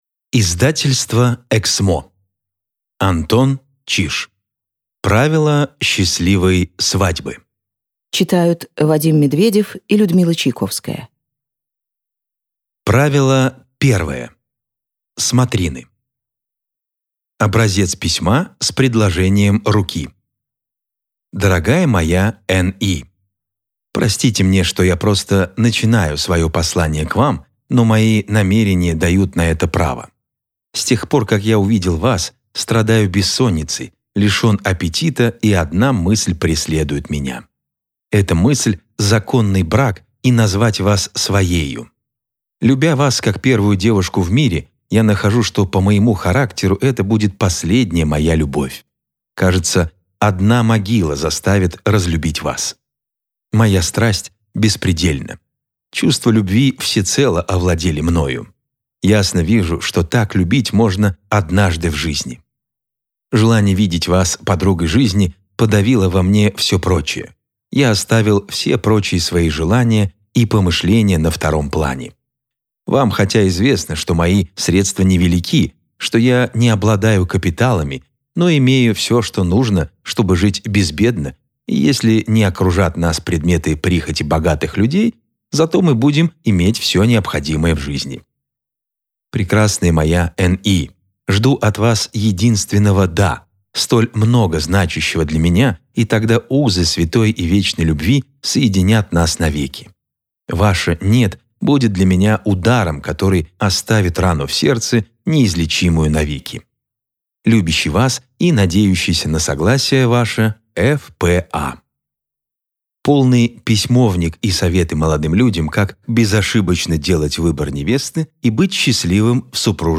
Аудиокнига Правила счастливой свадьбы | Библиотека аудиокниг